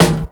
• Fresh Clean Hip-Hop Acoustic Snare Sample D# Key 42.wav
Royality free snare drum sound tuned to the D# note. Loudest frequency: 1465Hz
fresh-clean-hip-hop-acoustic-snare-sample-d-sharp-key-42-oed.wav